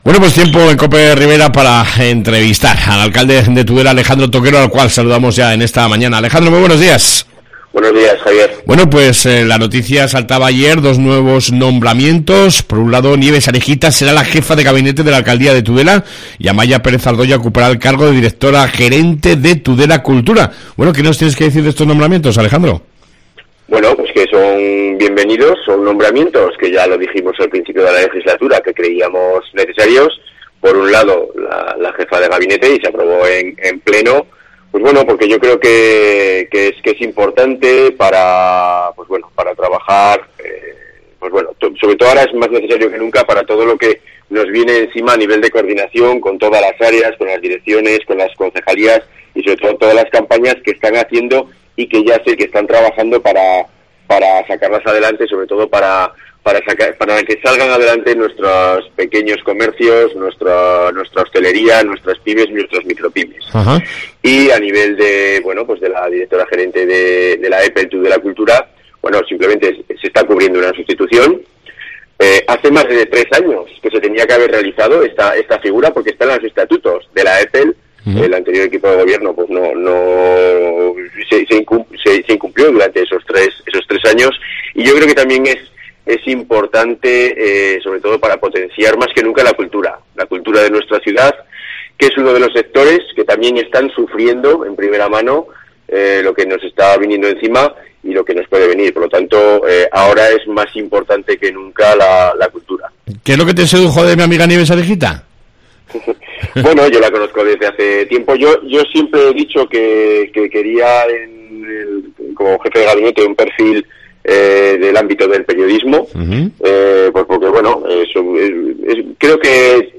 AUDIO: Entrevista con el Alcalde de Tudela Alejandro Toquero